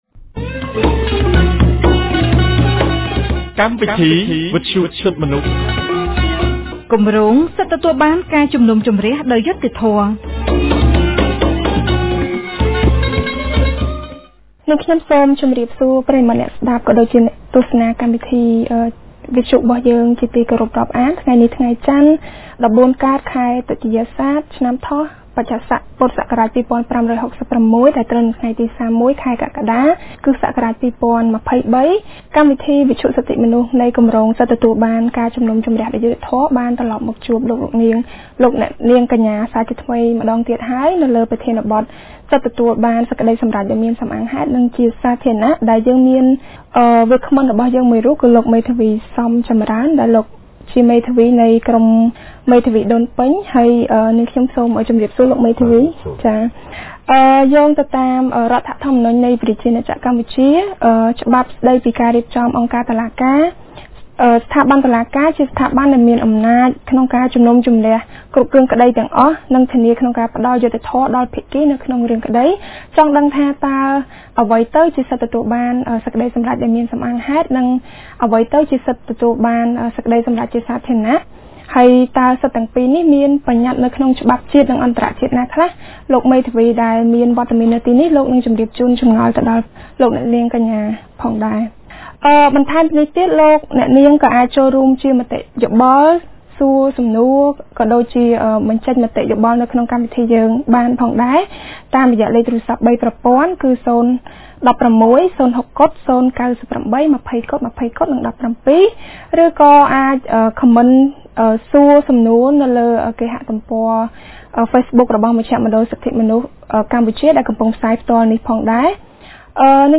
កាលពីថ្ងៃច័ន្ទ ទី៣១ ខែកក្កដា ឆ្នាំ២០២៣ ចាប់ពីវេលាម៉ោង១១:០០ ដល់ម៉ោង ១២:០០ថ្ងៃត្រង់ គម្រាងសិទ្ធិទទួលបានការជំនុំជម្រះដោយយុត្តិធម៌នៃមជ្ឈមណ្ឌលសិទ្ធិមនុស្សកម្ពុជា បានរៀបចំកម្មវិធីវិទ្យុក្រោមប្រធានបទស្តីពី សិទ្ធិទទួលបានសេចក្តីសម្រេចដោយមានសំអាងហេតុ និងជាសាធារណៈ